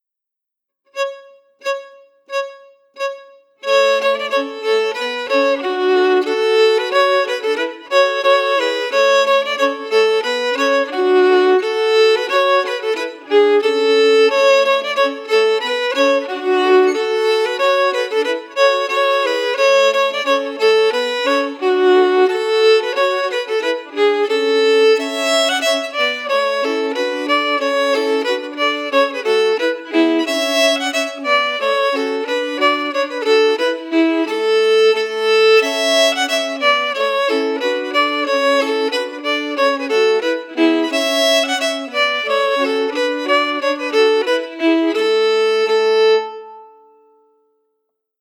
Key: A
Form: Reel